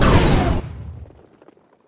youhit3.ogg